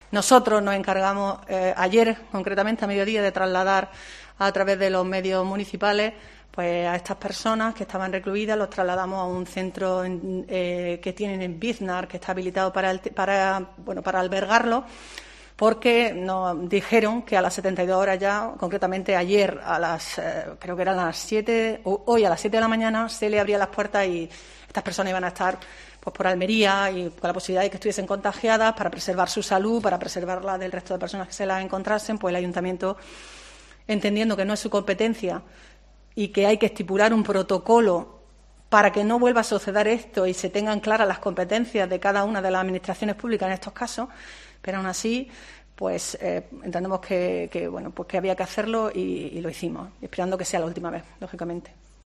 María Vázquez, primer teniente de alcalde de Almería y portavoz del equipo de gobierno